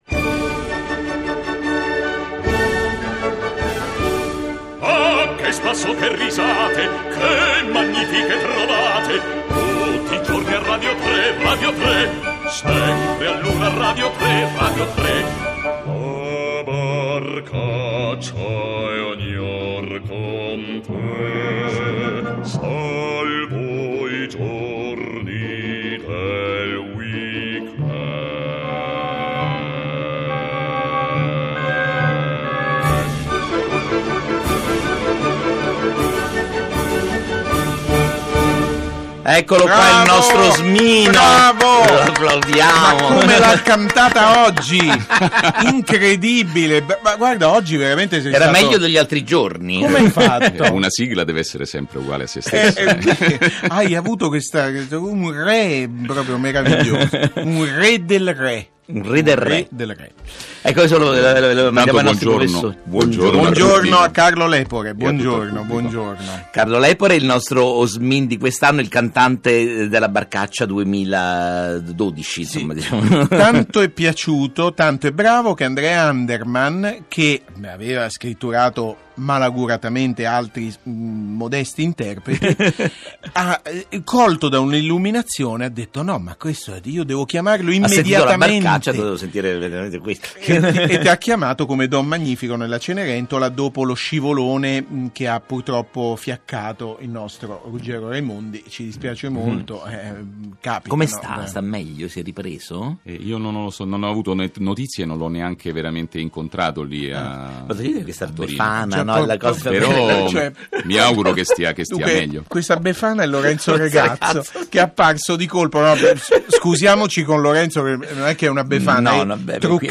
Sigla della Trasmissione "Barcaccia" e intervista del 13 giugno 2012